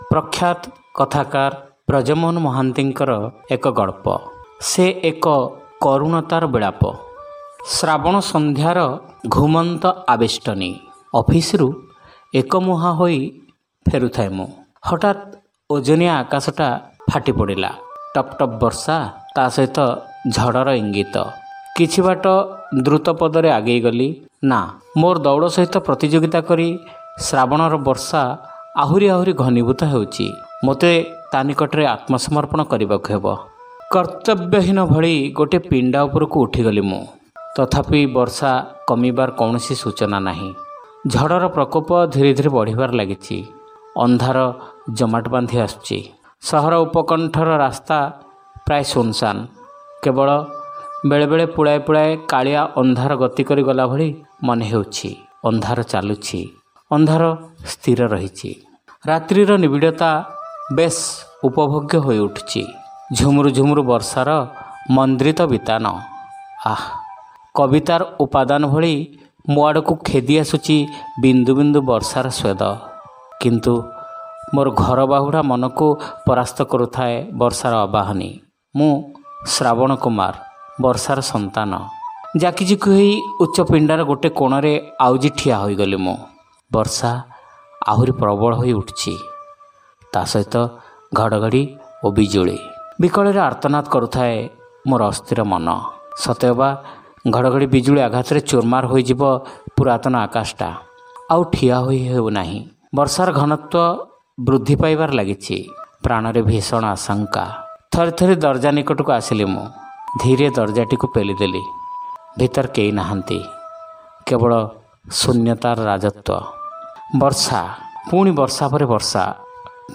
ଶ୍ରାବ୍ୟ ଗଳ୍ପ : ସେ ଏକ କରୁଣତାର ବିଳାପ